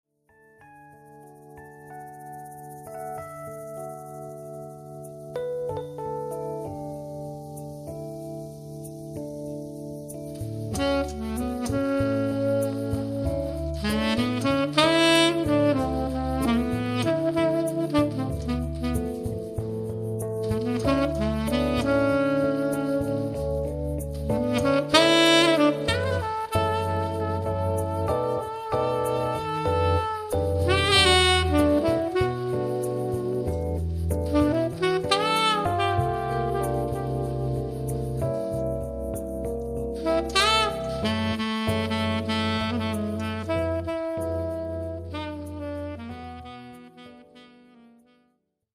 piano and saxophone
Cool and classy lounge sounds